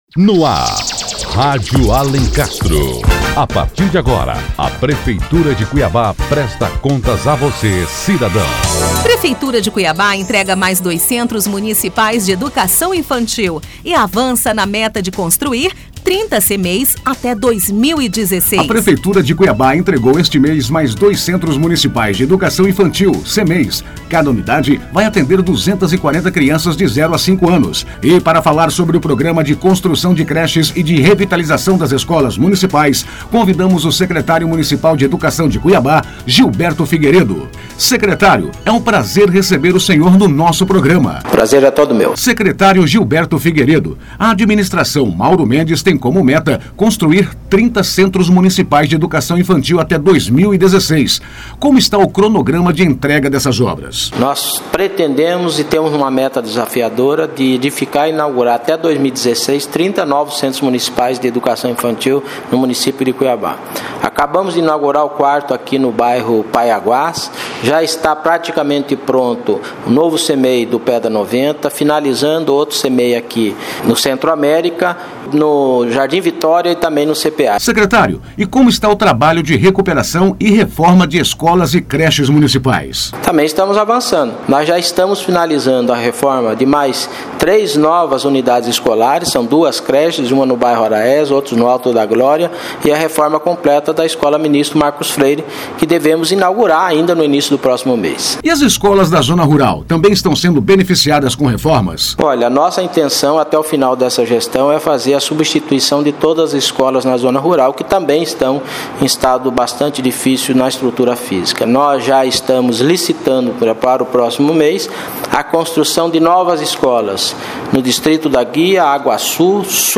Para falar um pouco mais sobre este projeto, convidamos o Secretário Municipal de Educação, Gilberto Gomes, para participar desta edição. Confira a entrevista.